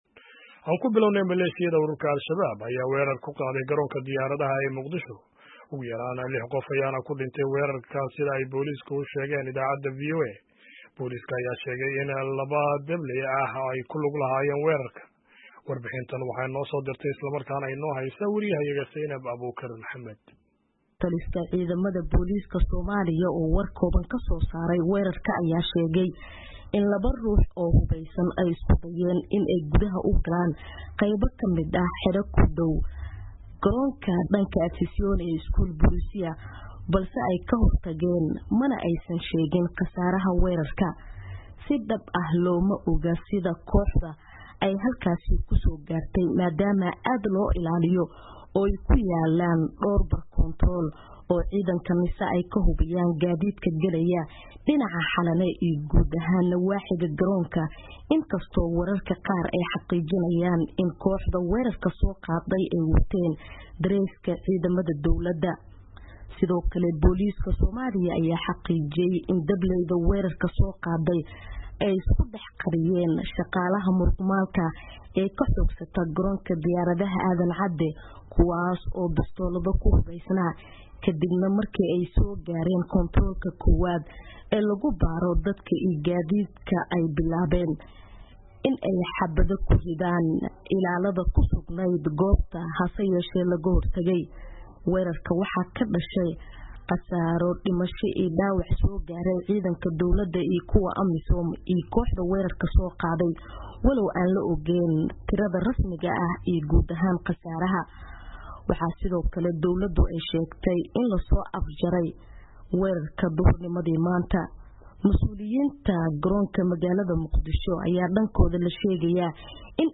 War Deg-Deg ah
warbixintan kasoo dirtay Muqdisho.